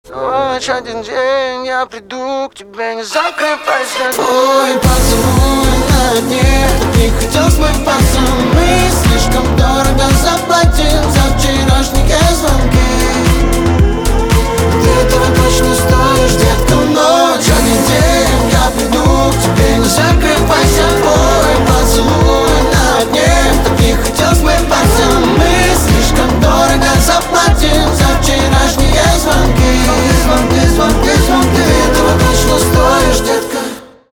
поп
скрипка , битовые , басы
чувственные